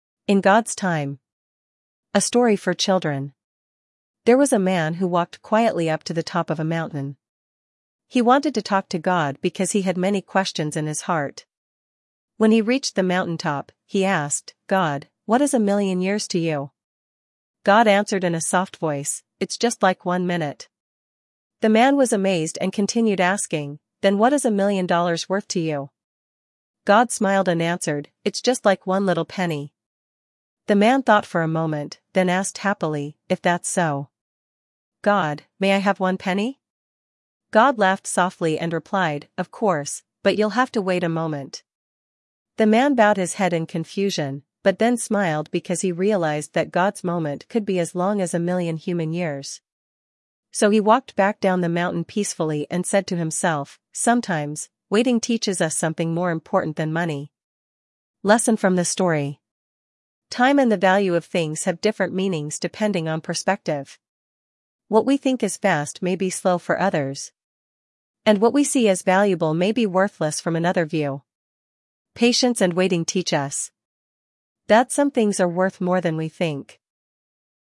Story
A Story for Children